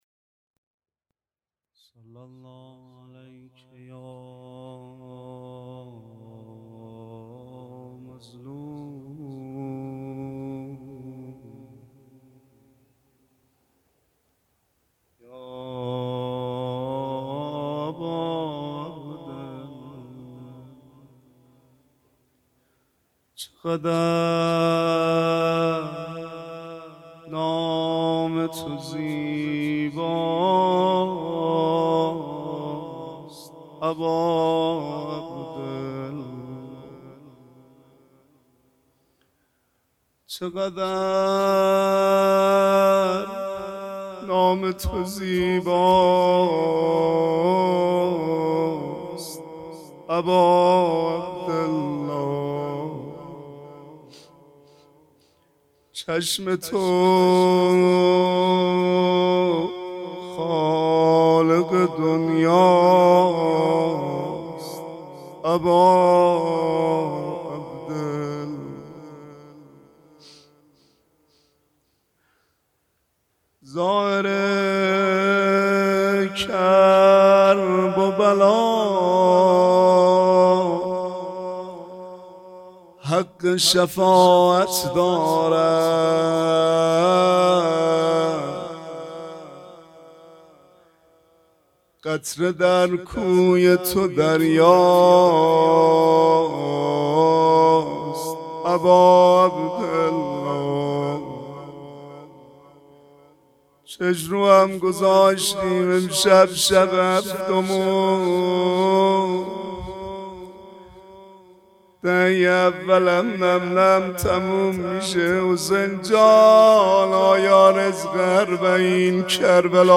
شب هفتم محرم ۱۴۴۴